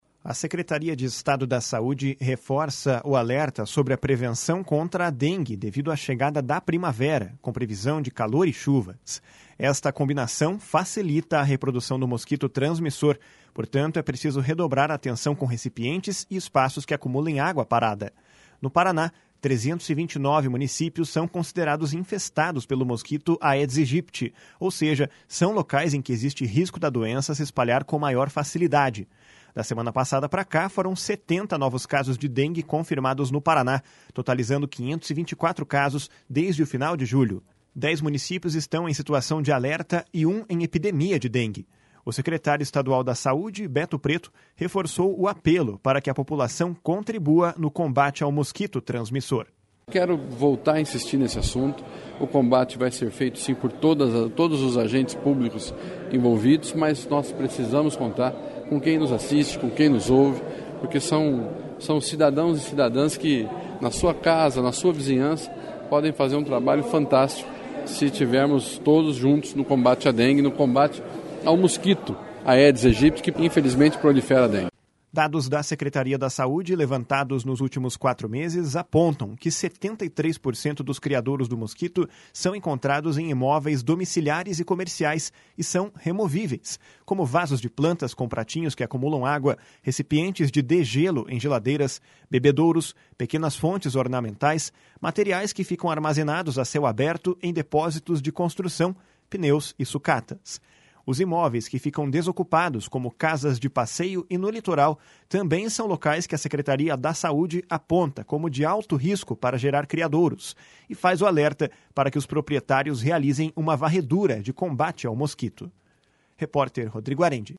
O secretário estadual da Saúde, Beto Preto, reforçou o apelo para que a população contribua no combate ao mosquito transmissor. // SONORA BETO PRETO //